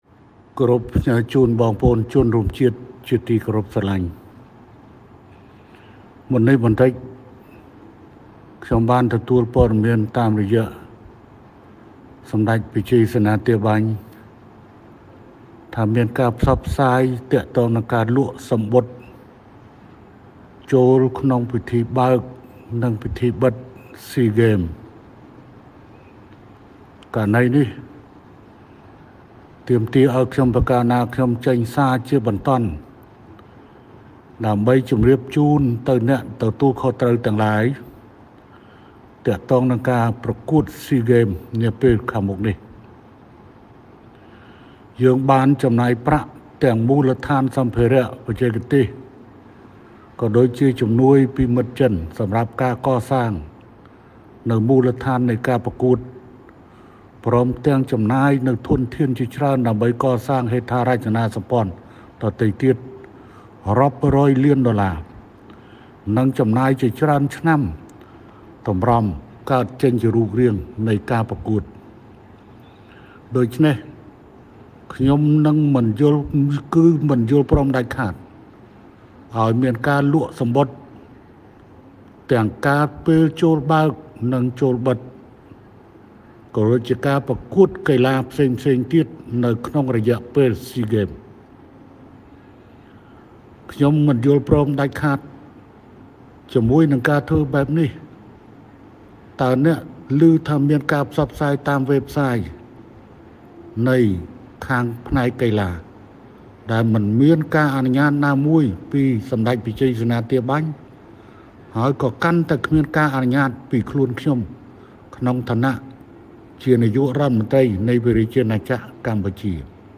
សារសំឡេងពិសេសរបស់ សម្ដេចតេជោ ហ៊ុន សែន
BREAKING NEWS :សារសំឡេងពិសេសរបស់ សម្ដេចតេជោ ហ៊ុន សែន ដាក់បទបញ្ជាមិនឱ្យមានការលក់សំបុត្រចូលទស្សនាក្នុងការប្រកួតកីឡា SEA GAMES នោះទេ ទាំងពេលបើក-បិទ និងការប្រកួតគ្រប់វិញ្ញាសារទាំងអស់ ដោយប្រជាពលរដ្ឋអាចចូលរួមទស្សនាដោយសេរី ។